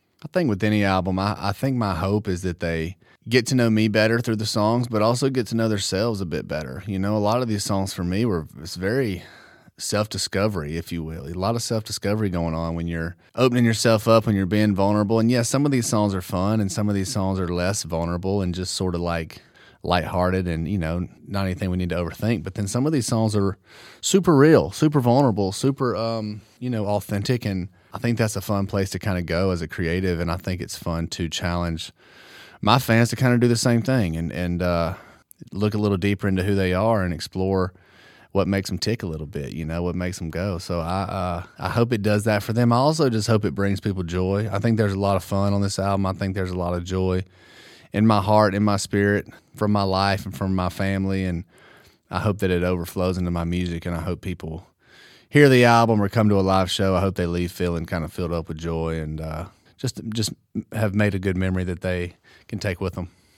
Tyler Hubbard explains what he hopes fans go away with after listening to his new album Strong.